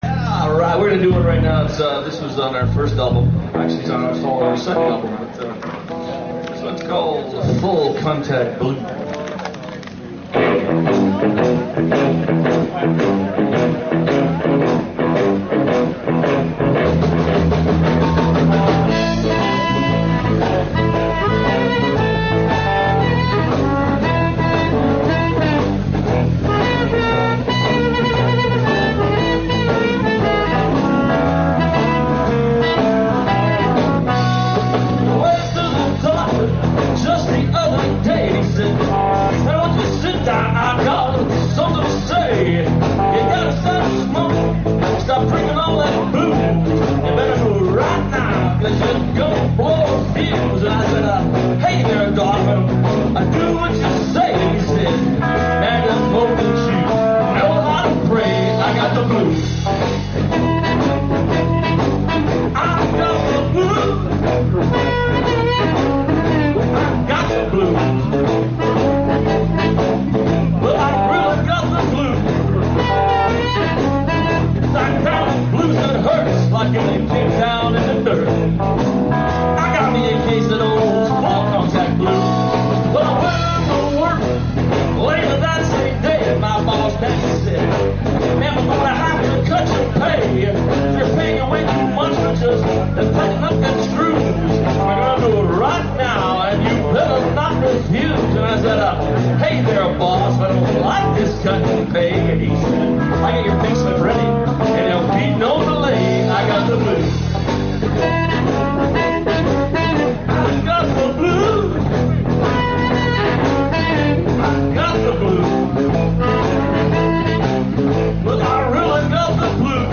harmonica and lead vocals
guitar and vocals
bass and vocals
blues-rock